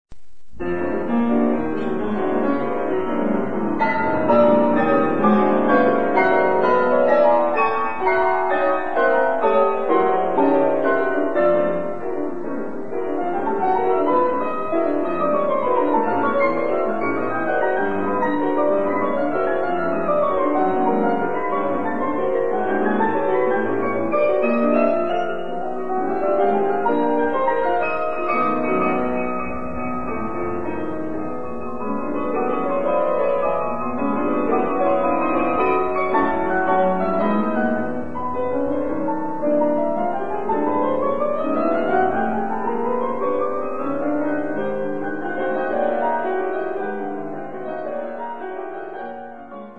duo de pianos